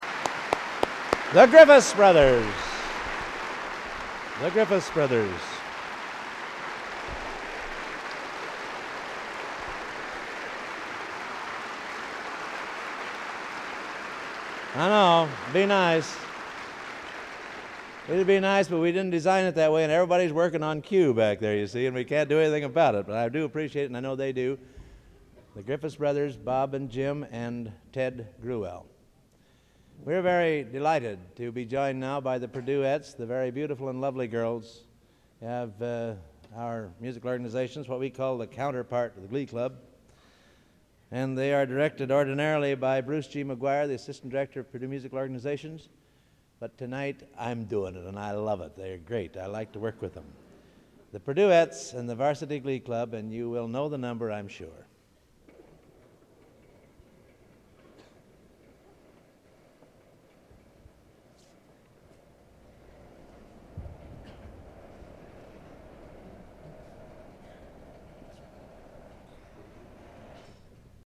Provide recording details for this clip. Collection: Gala Concert, 1961